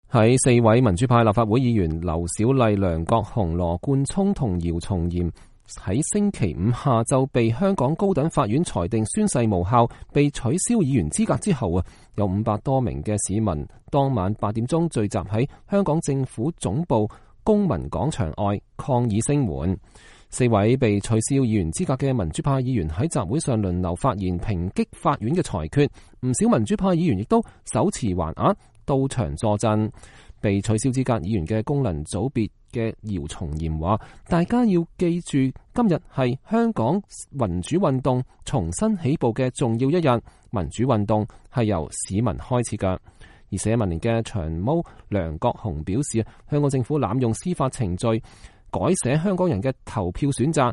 四位被取消議員資格的民主派議員在集會上輪流發言，抨擊法院的裁決。